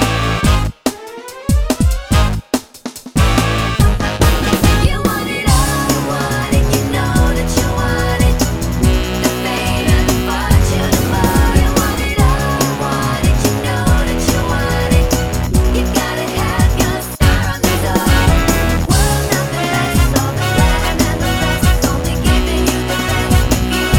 No Backing Vocals Soundtracks 4:34 Buy £1.50